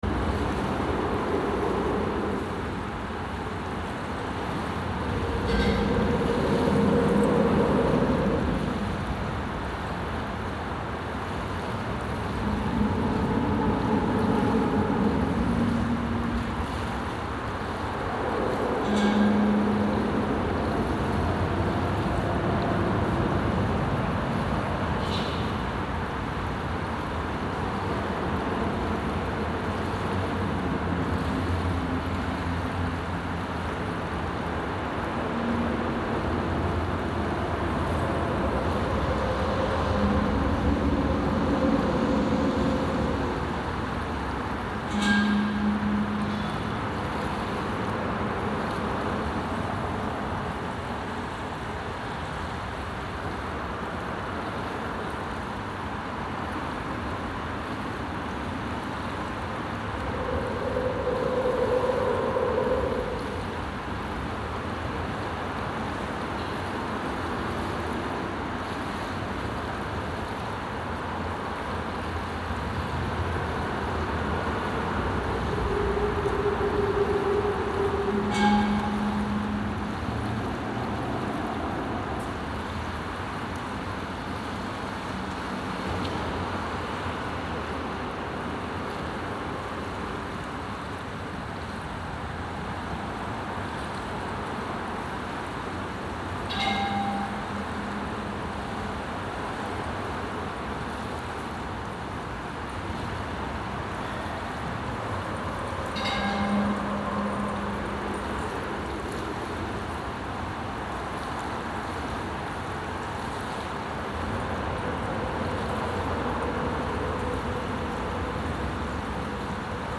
Underneath an interstate bridge, by the creek